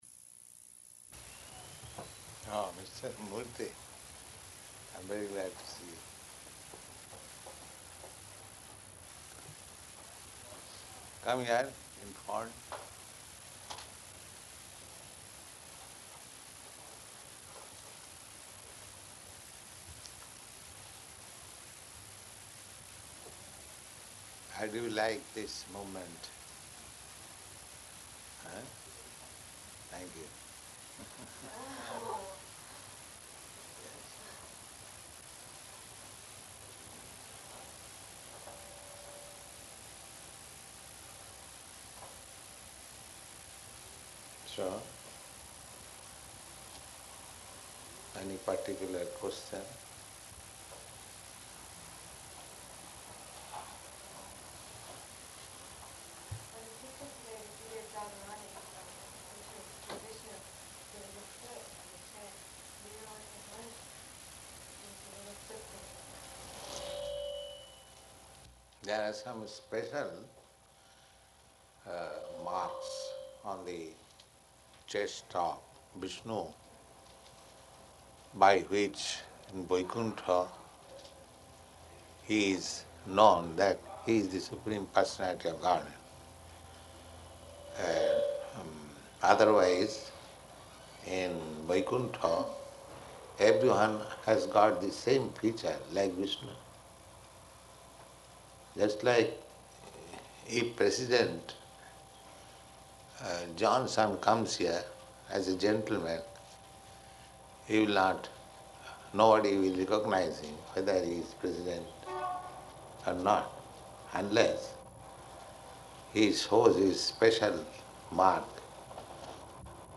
Room Conversation
-- Type: Conversation Dated: April 11th 1969 Location: New York Audio file: 690411R1-NEW_YORK.mp3 [Audio level low until 1:18] Prabhupāda